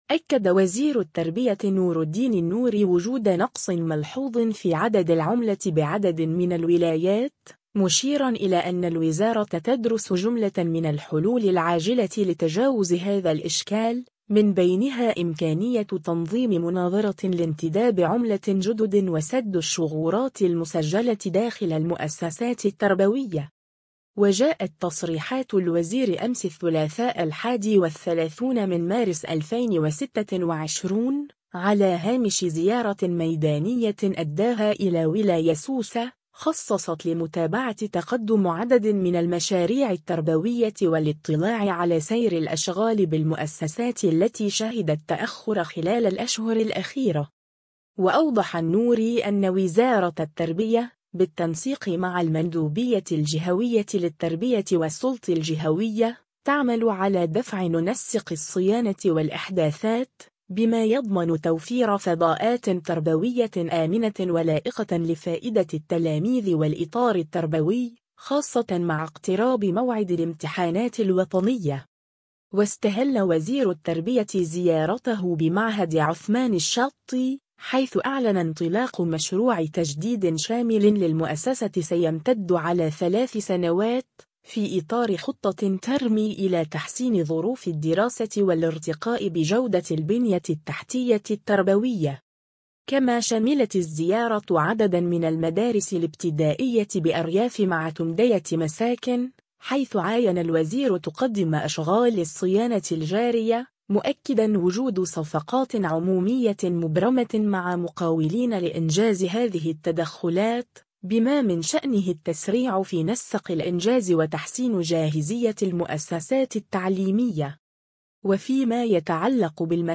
وجاءت تصريحات الوزير أمس الثلاثاء 31 مارس 2026، على هامش زيارة ميدانية أداها إلى ولاية سوسة، خصصت لمتابعة تقدم عدد من المشاريع التربوية والاطلاع على سير الأشغال بالمؤسسات التي شهدت تأخراً خلال الأشهر الأخيرة.